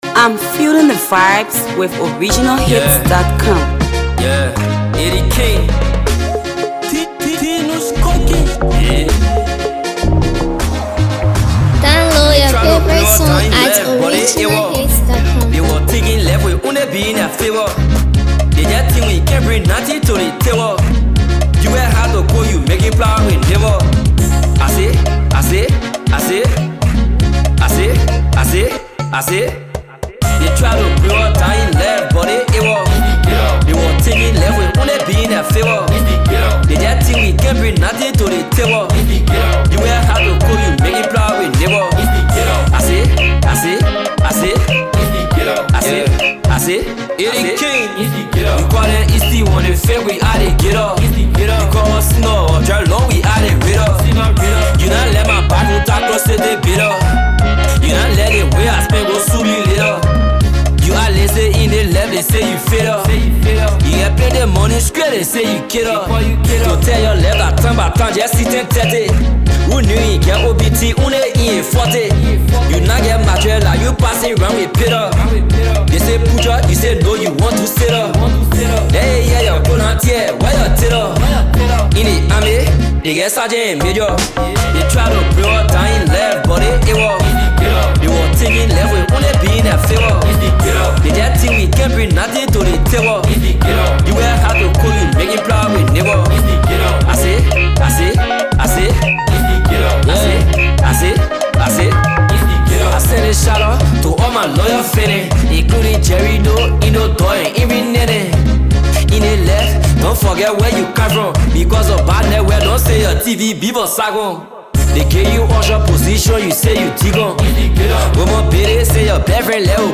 Liberian Hipco rapper